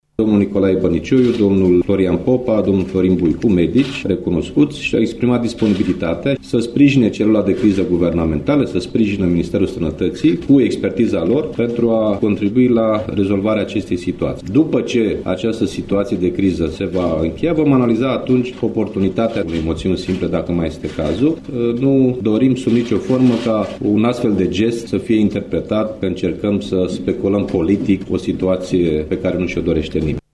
Preşedintele PSD, Liviu Dragnea, a precizat că mai mulţi medici recunoscuţi, membri ai partidului, şi-au exprimat disponibilitatea de a ajuta la rezolvarea acestei crize: